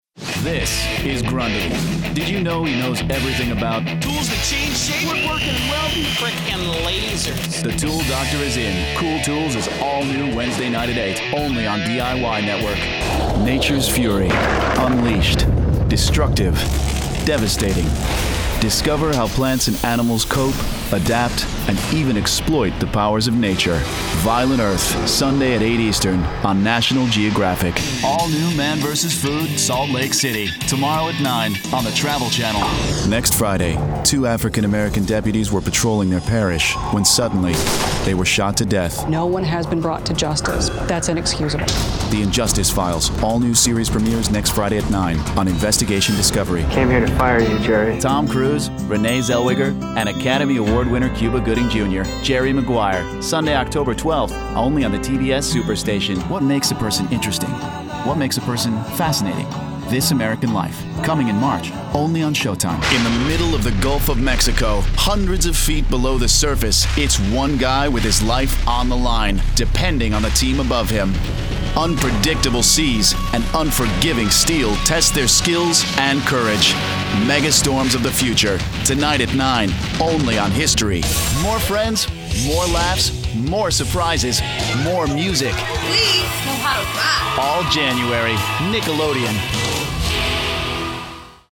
Promo Demo